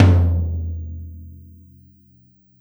Tom Shard 01.wav